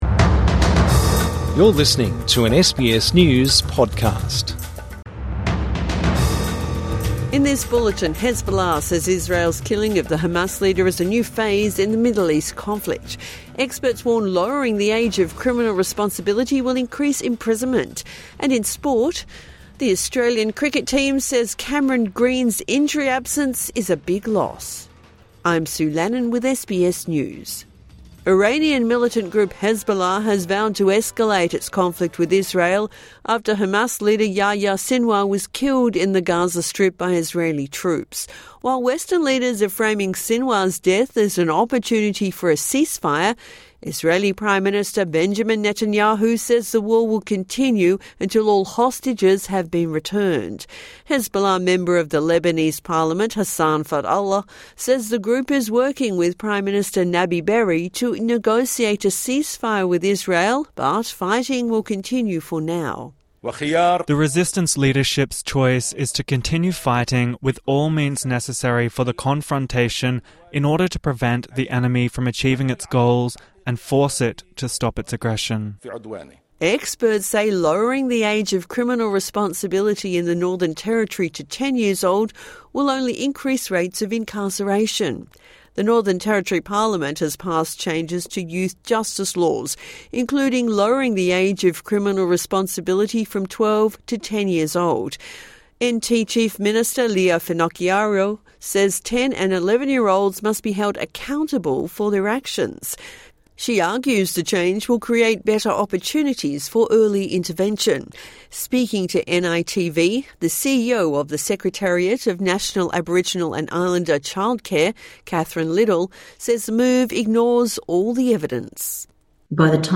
Evening News Bulletin October 18, 2024